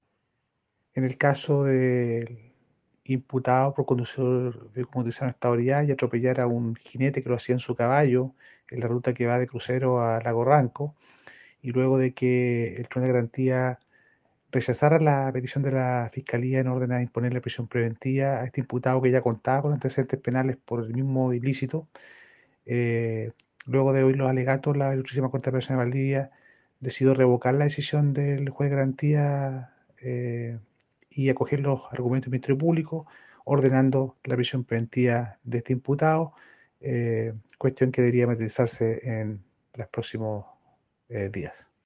Fiscal Sergio Fuentes.